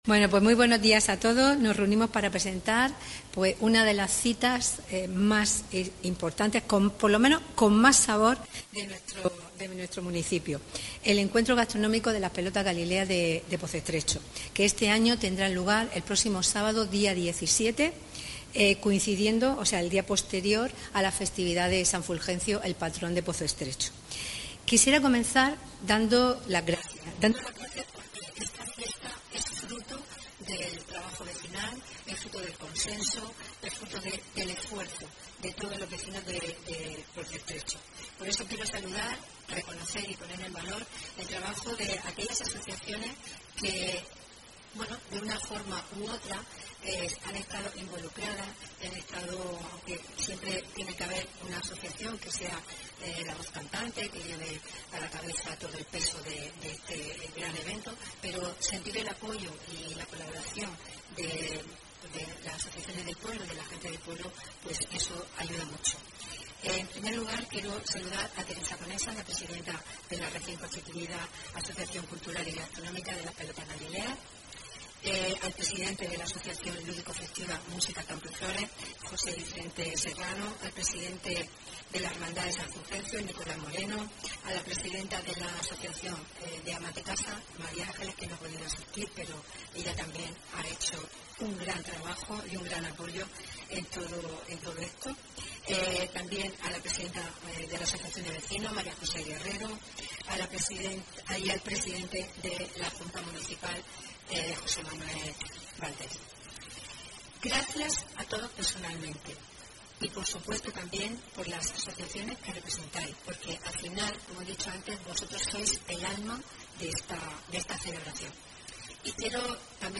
Enlace a Declaraciones de las ediles Francisca Martínez y Beatriz Sánchez.